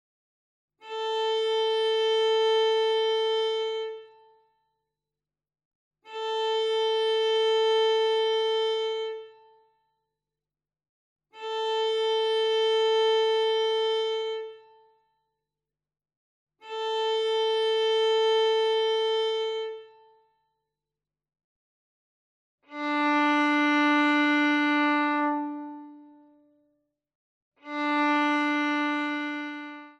Stimmtöne
Geige stimmen
VHR 3800_Stimmtöne.mp3